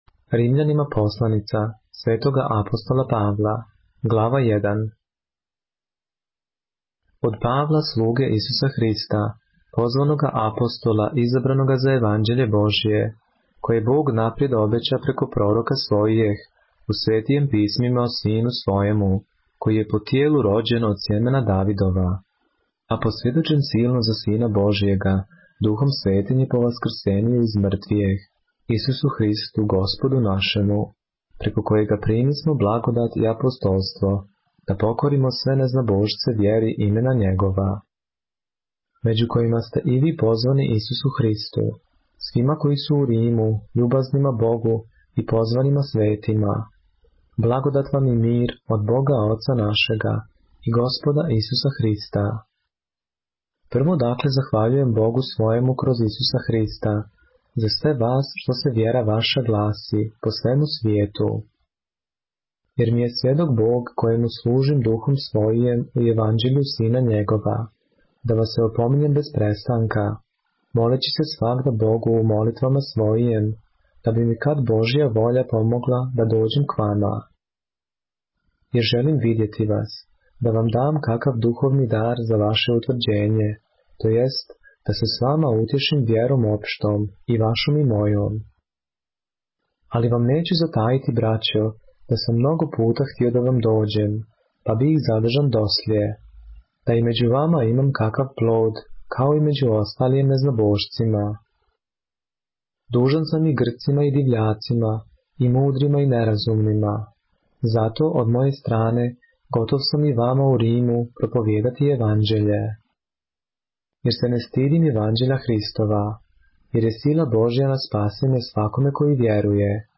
поглавље српске Библије - са аудио нарације - Romans, chapter 1 of the Holy Bible in the Serbian language